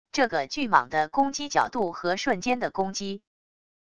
这个巨蟒的攻击角度和瞬间的攻击wav音频